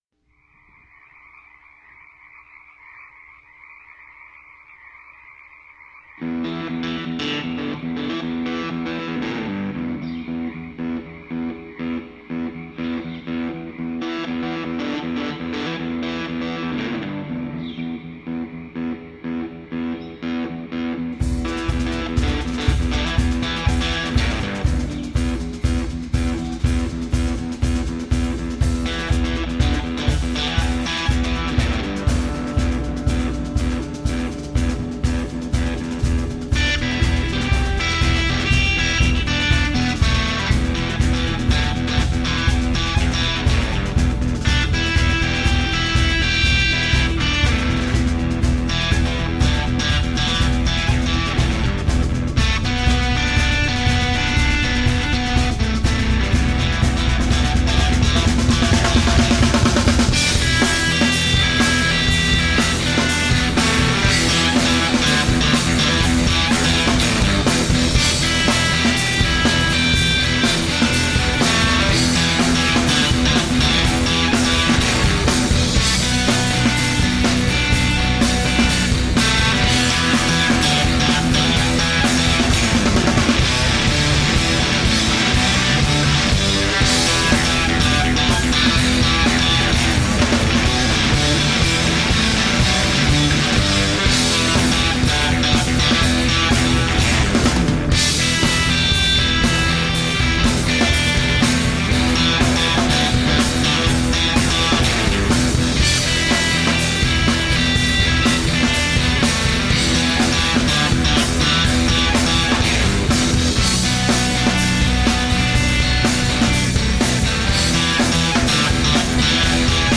Original, blues-based rock and roll.
and guitar players
Tagged as: Hard Rock, Alt Rock, Instrumental